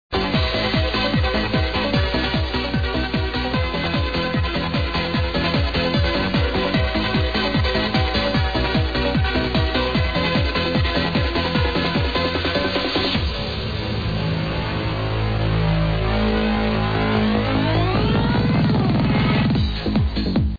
It was taken from a radio show (first half of 1997).